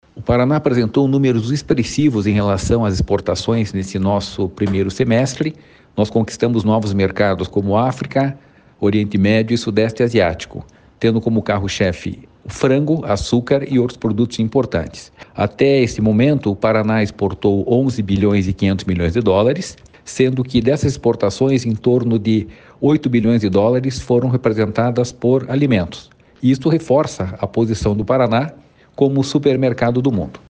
Sonora do diretor-presidente do Ipardes, Jorge Callado, sobre as exportações do Paraná no 1º semestre de 2024